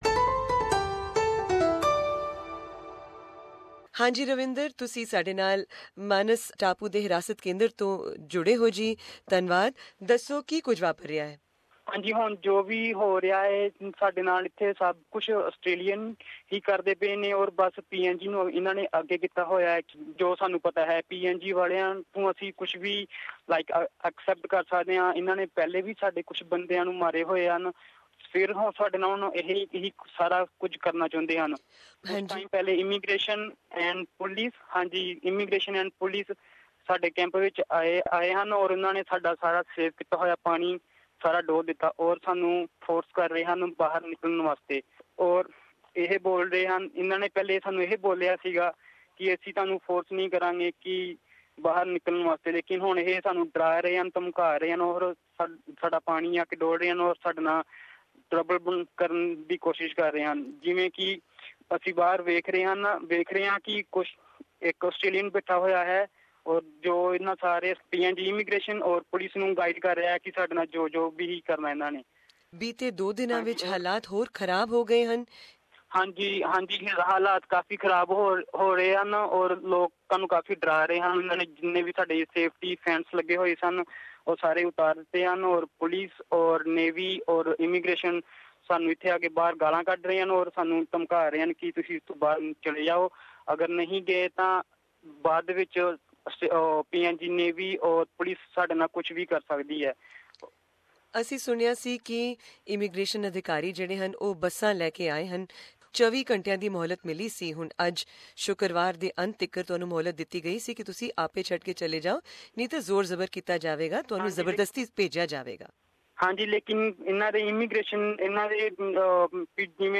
To hear read more about this interview click on the link below: READ MORE 'They are dismantling everything and threatening to use force now' For more stories, follow SBS Punjabi on Facebook and Twitter .